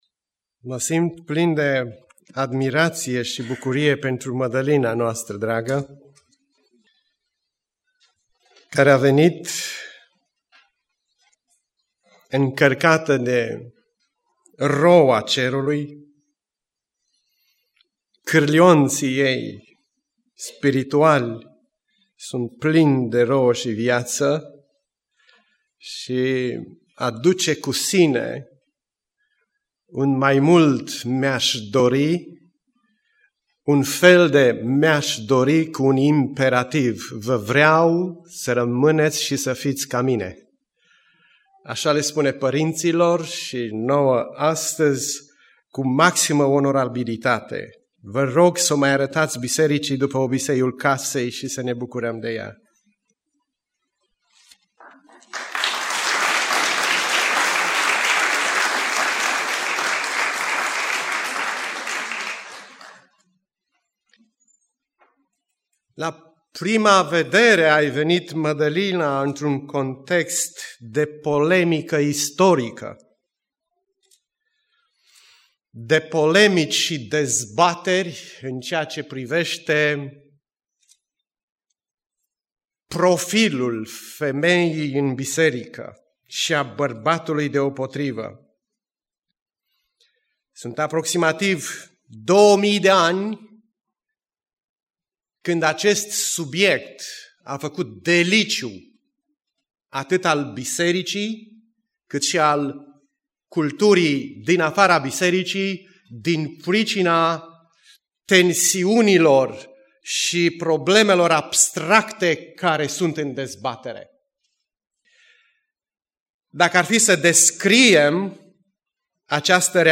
Predica Aplicatie 1 Timotei 2:8-15